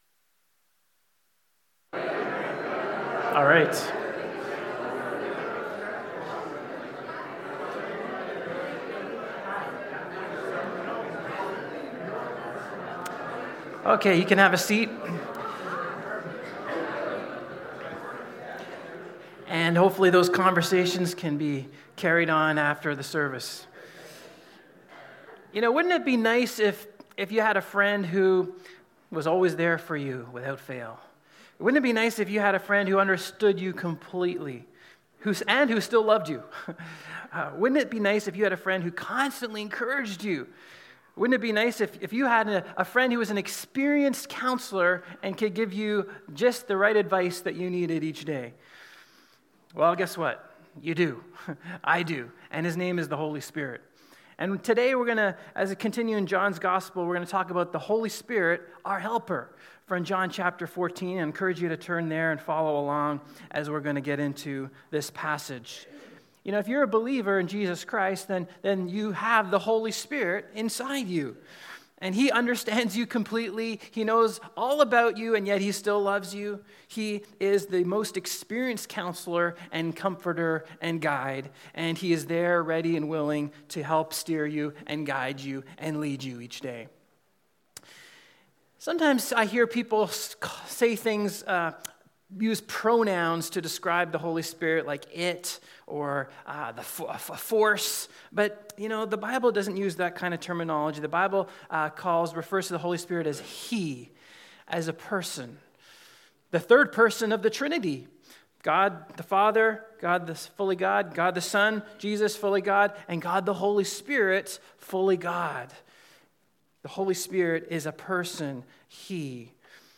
John 14:12-31 John’s Gospel (talk 30), Preached on January 05, 2020 http